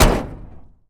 carKick3.ogg